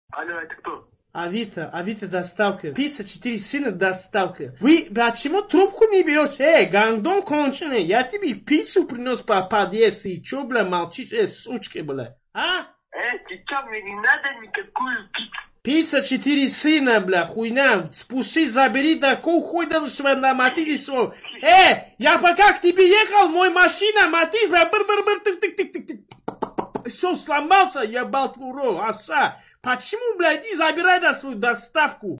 Category: Meme Soundboard